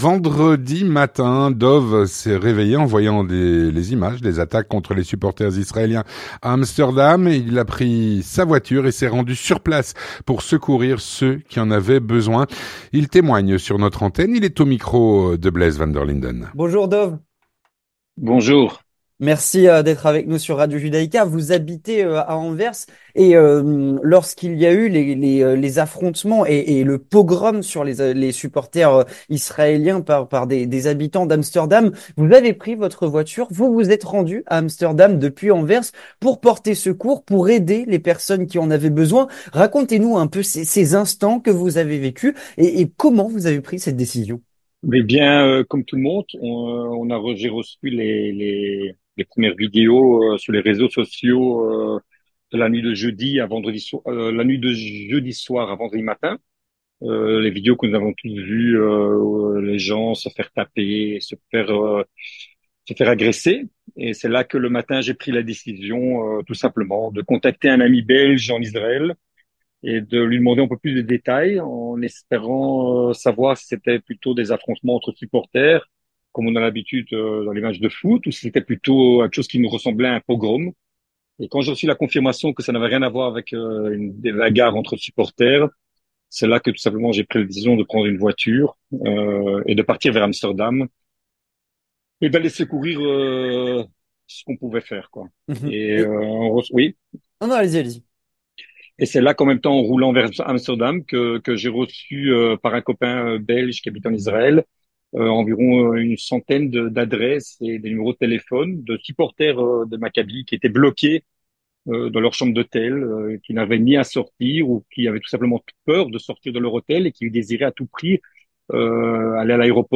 L'entretien du 18H